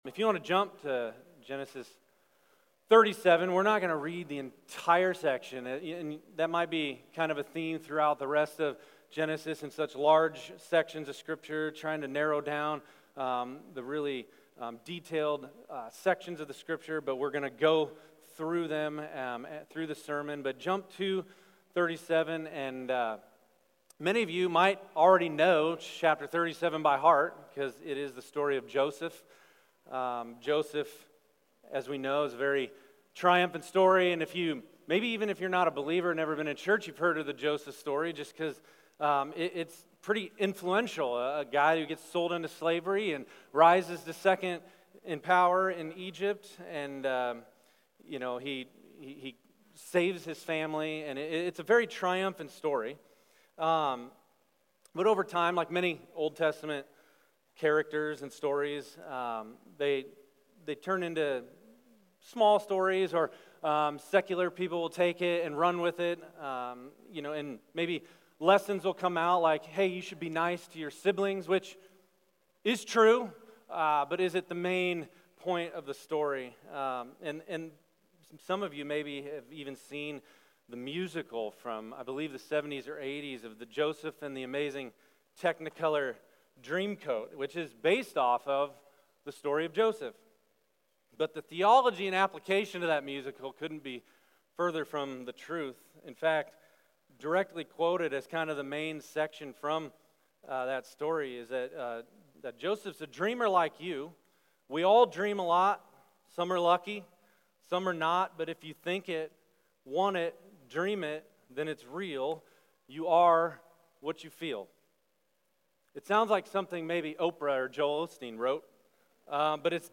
Sermon Questions: Read Genesis 37 Part 1: Family Strife and Favoritism Read: Genesis 37:1–4 What was Joseph’s family like?